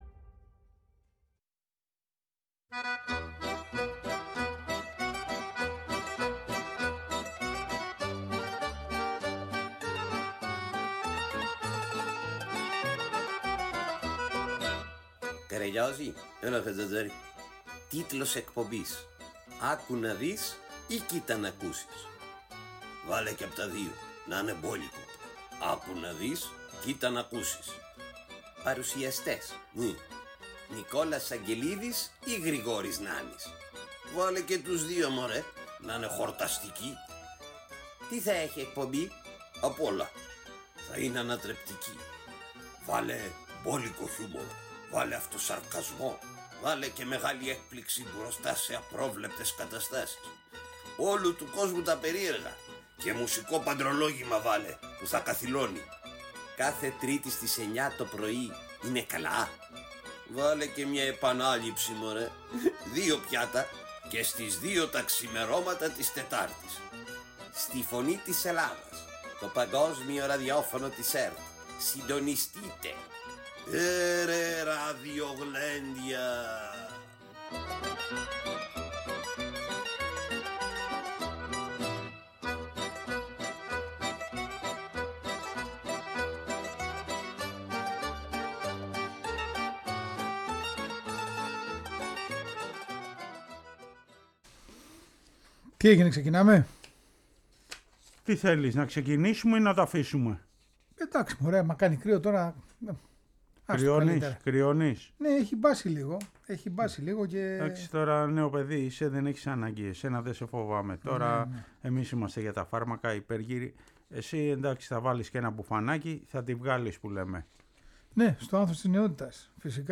Τέλος ακούμε τον ύμνο του Ναυπακτιακού Αστέρα και μαθαίνουμε την ιστορία του